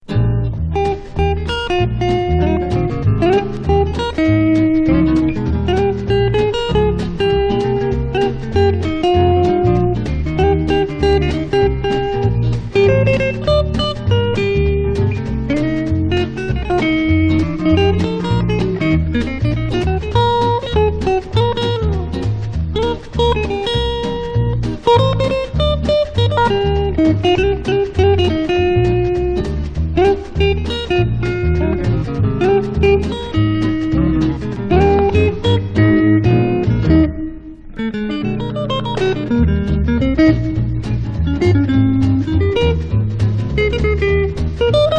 Pop, Jazz, Easy Listening　Germany　12inchレコード　33rpm　Stereo